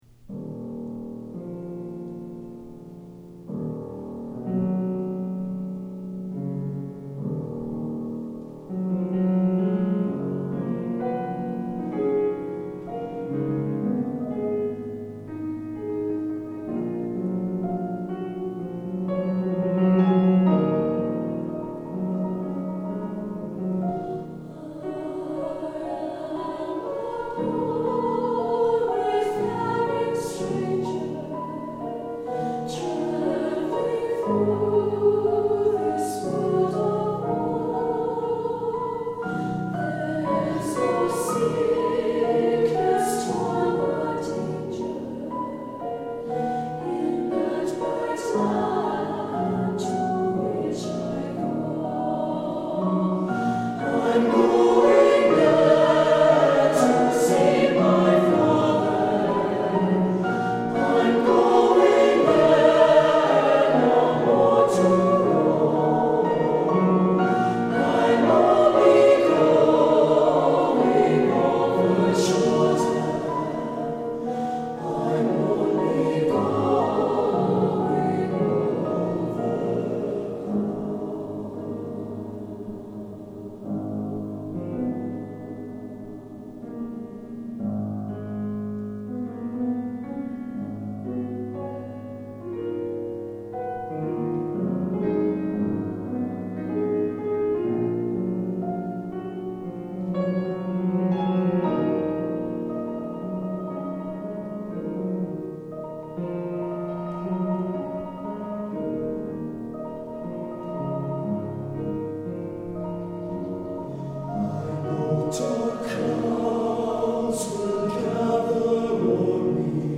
Voicing: SATB and 4 Hand Piano